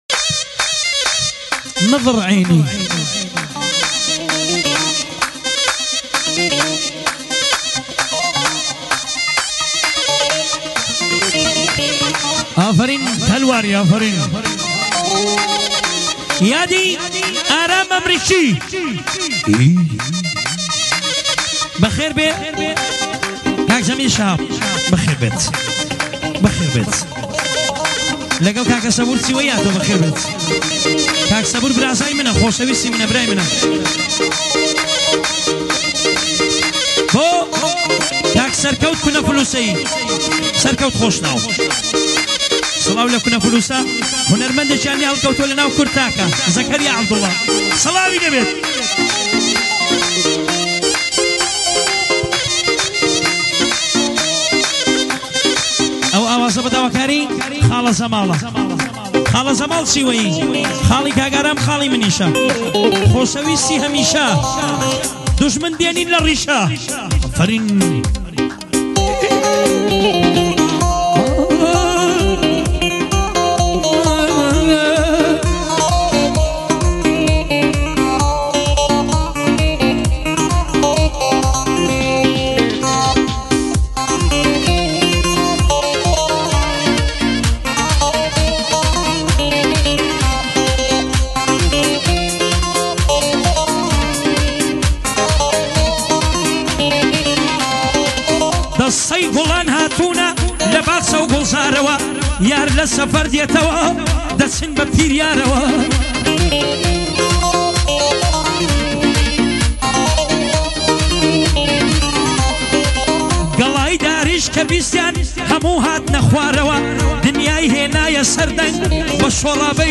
موزیک قدیمی و بسیار شاد و دلنشین
دانلود اهنگ کردی